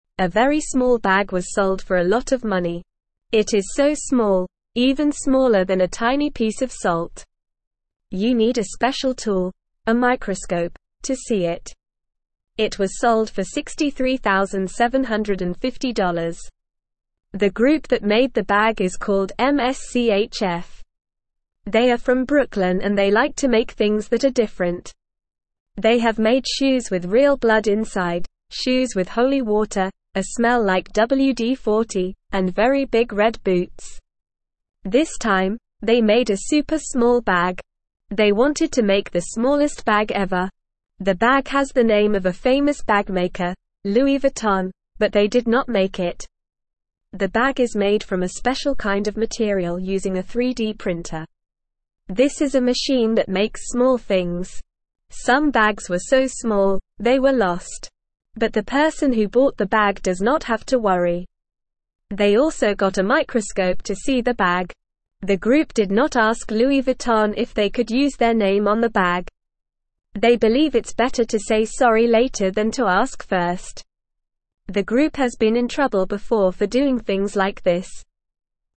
Normal
English-Newsroom-Beginner-NORMAL-Reading-Tiny-Bag-Sold-for-Big-Money.mp3